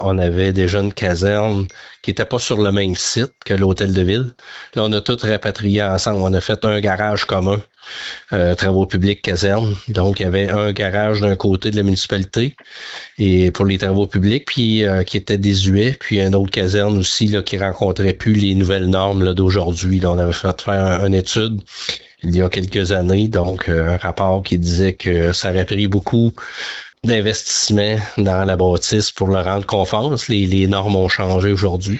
Lors de l’entrevue, le maire a rappelé que la Municipalité devait entretenir 78 km de route, dont 7 km appartiennent au ministère des Transports.